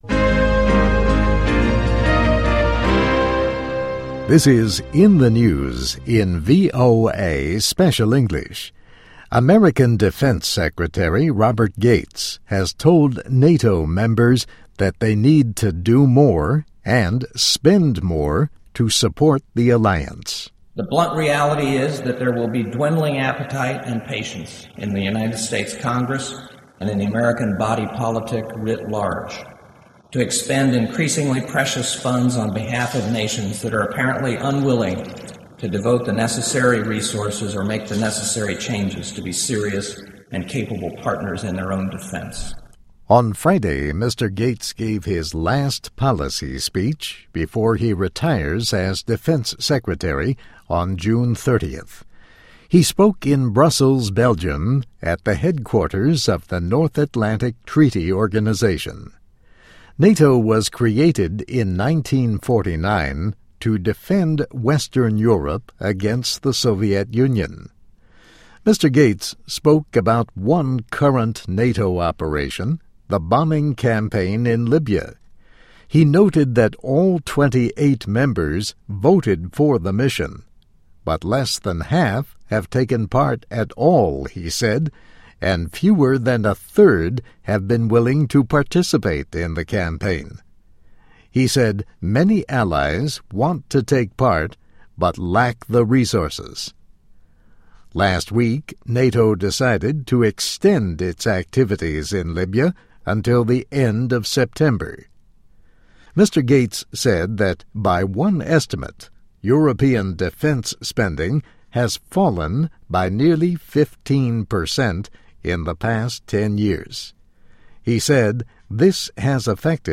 美国之音VOA Special English > In the News